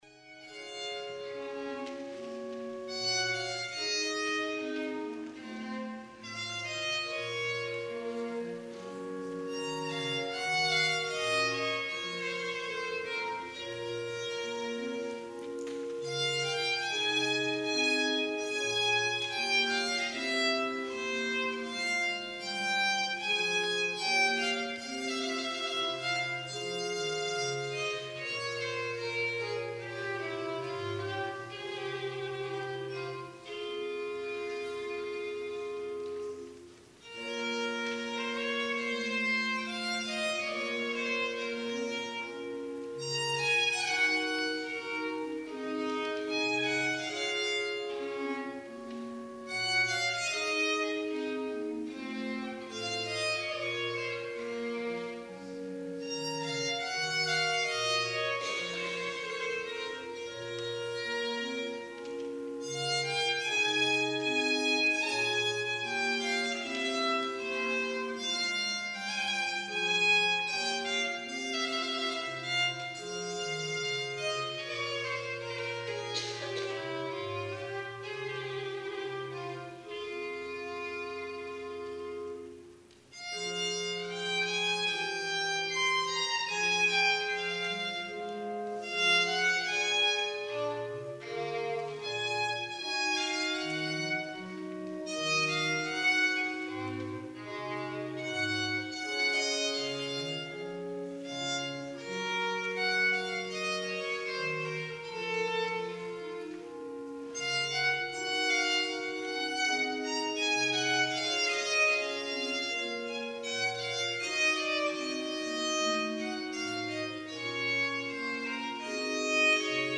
PRELUDE Preludio
violin